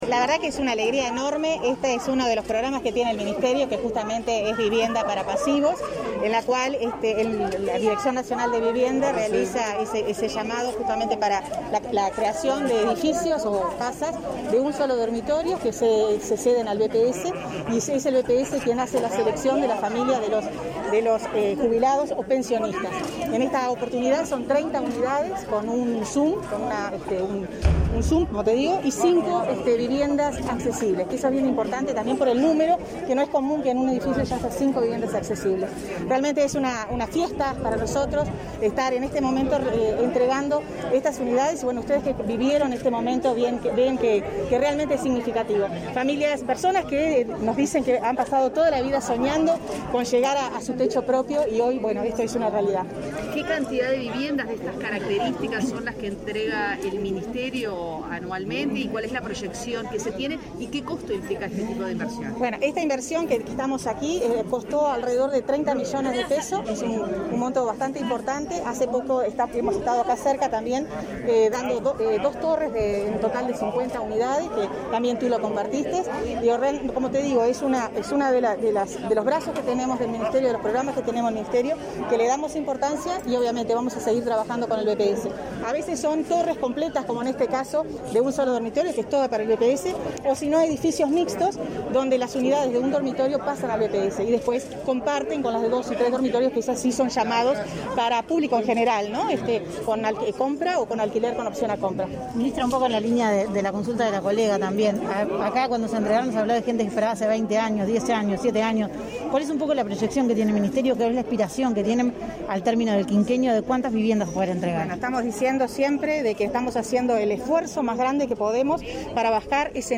Declaraciones a la prensa de la ministra de Vivienda, Irene Moreira
Declaraciones a la prensa de la ministra de Vivienda, Irene Moreira 30/11/2021 Compartir Facebook X Copiar enlace WhatsApp LinkedIn Tras participar en la entrega de viviendas a jubilados y pensionistas de Montevideo, este 30 de noviembre, la ministra Irene Moreira efectuó declaraciones a la prensa.